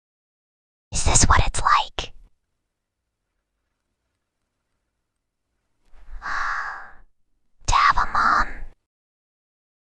File:Whispering Girl 4.mp3
Whispering_Girl_4.mp3